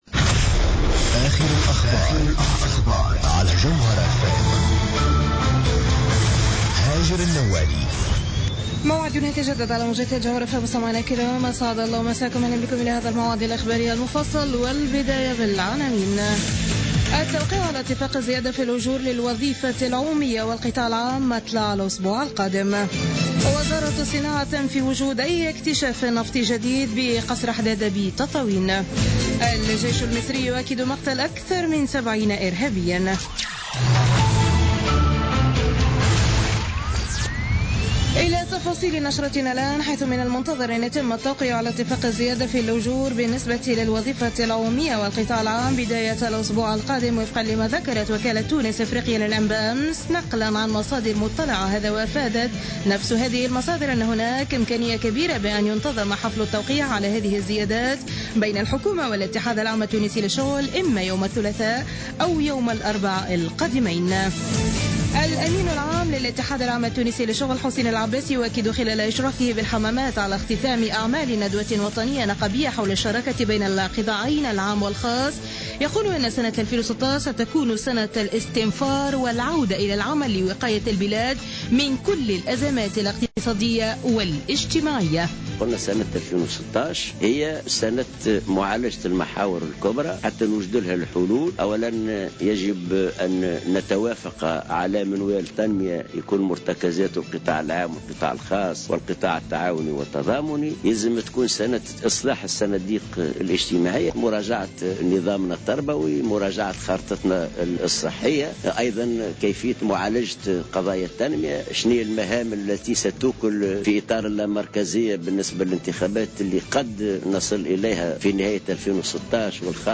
نشرة أخبار منتصف الليل ليوم الأحد 19 سبتمبر 2015